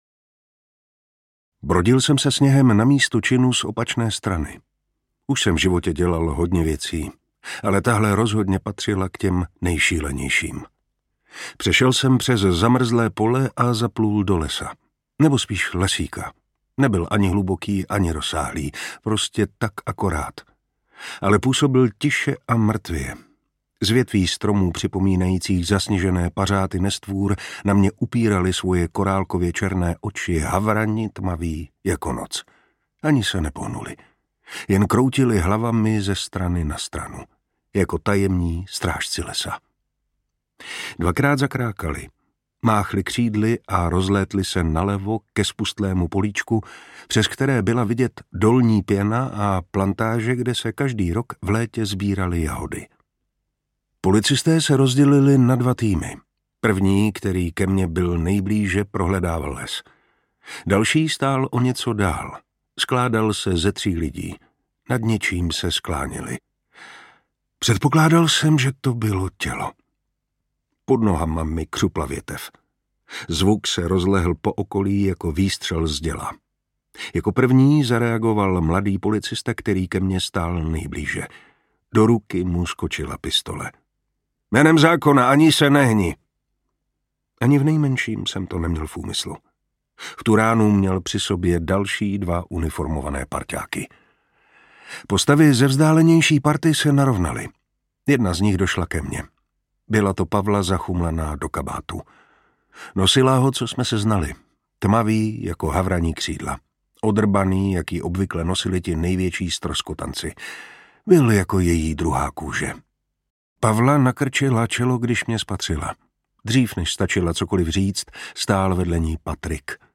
Zavátá sněhem audiokniha
Ukázka z knihy
• InterpretMartin Preiss